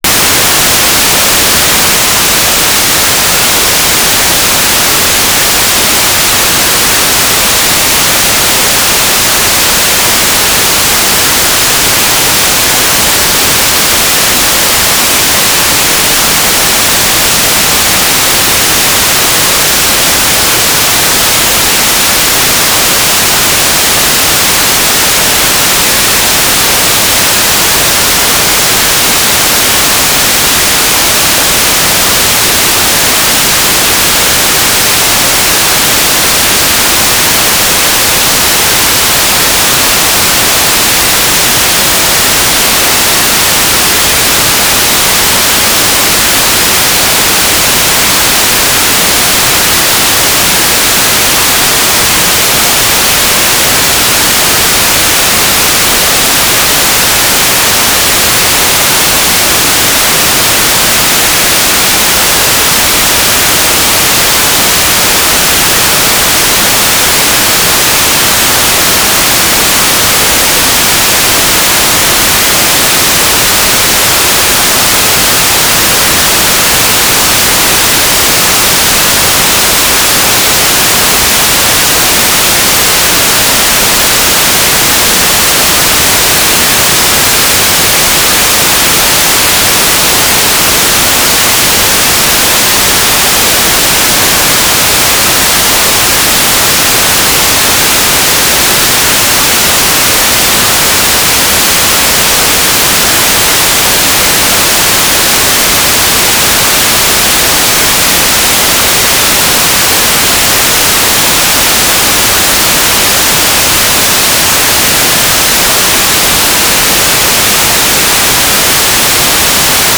"transmitter_description": "AFSK 1k2 TLM",
"transmitter_mode": "AFSK TUBiX10",